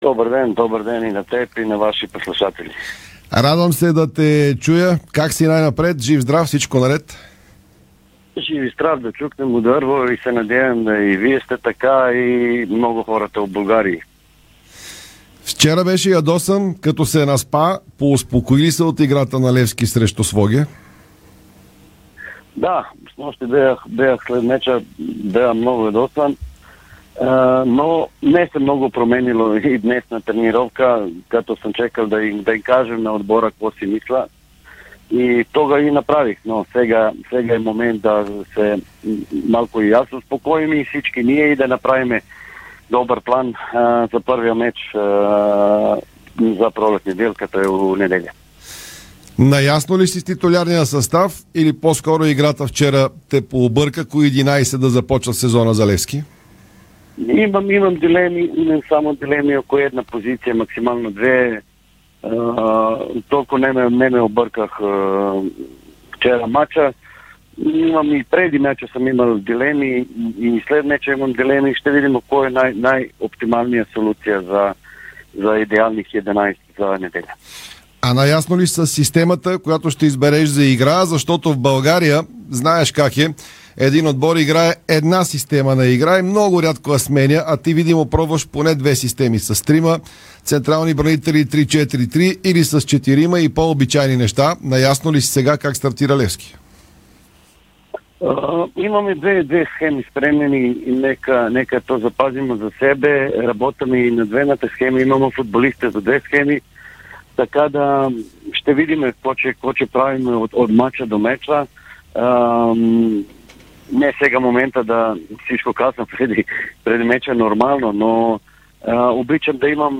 Старши треньорът на Левски Славиша Стоянович призна в ефира на Дарик радио, че е говорил с кипърския десен бек Драган Михайлович. Той разкри и че "сините" работят по две схеми на игра, които да ползват в мачовете.